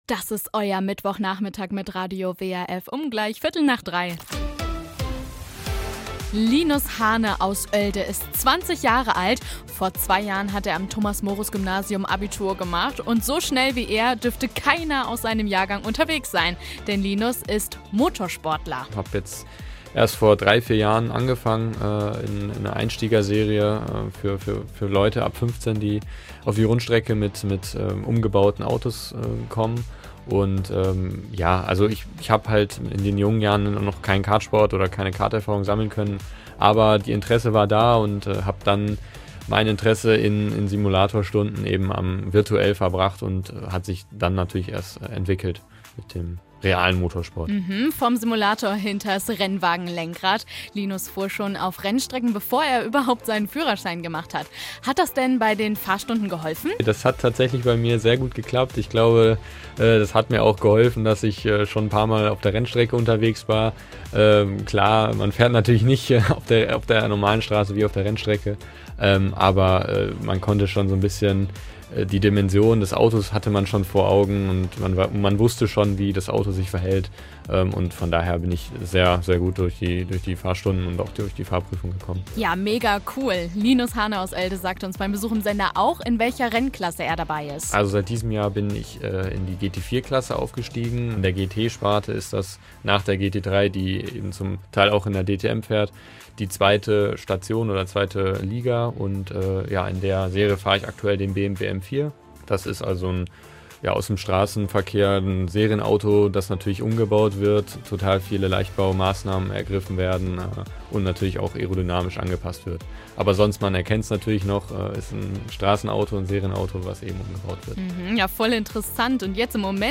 Komplettes Interview hier als